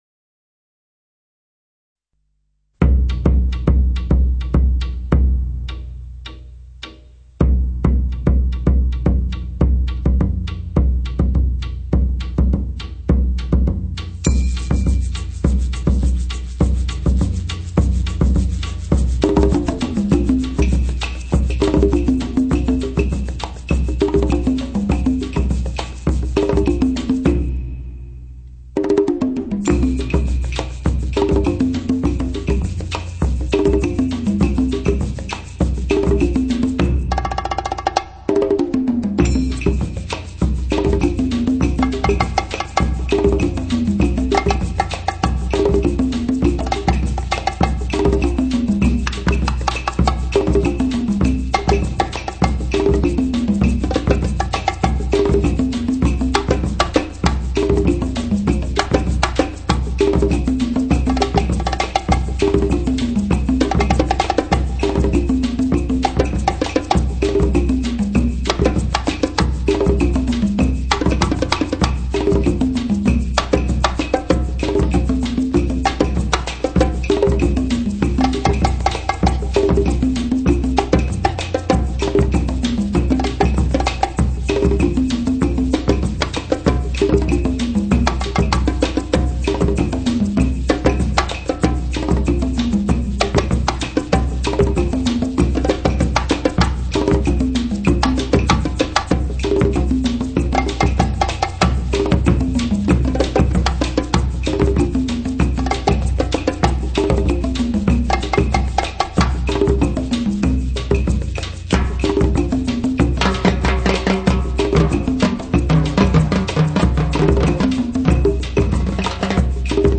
感觉低音非常丰富，澎湃有劲，音质清澈厚实，具有大动态 低失真的高保真特性。
那种沉重凌厉的极低频爆炸力，整个听音 室好似被炸闲一样，好得人惊。
使低频潜得更深，动态声场极 为宏大，一粒粒结实的低频音符飘然而出。